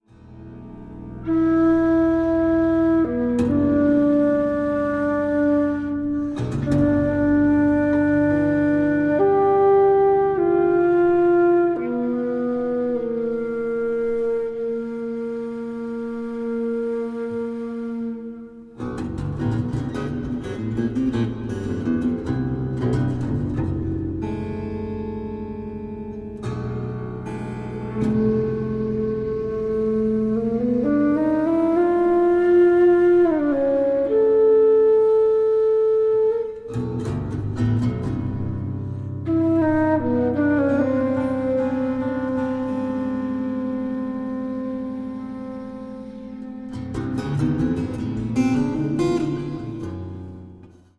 Tenor and Soprano saxophones, Alto flute, Bansuri flute